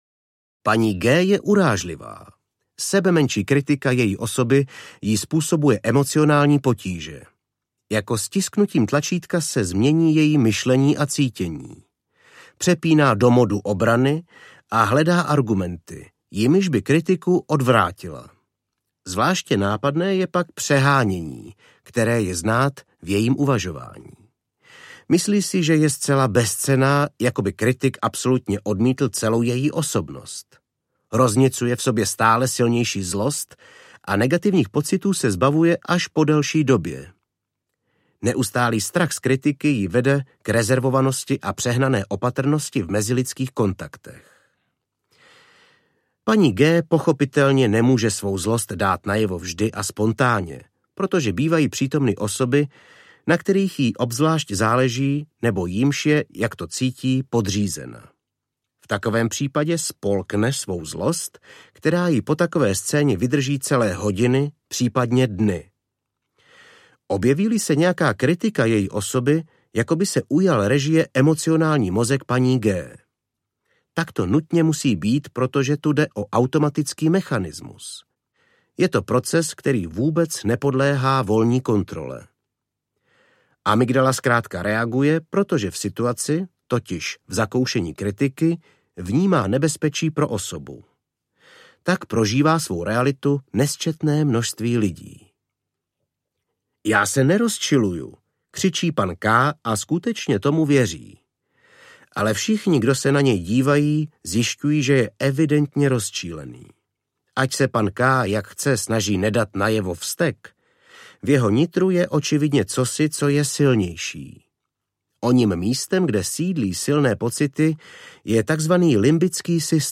Ztráta kontroly audiokniha
Ukázka z knihy
Vyrobilo studio Soundguru.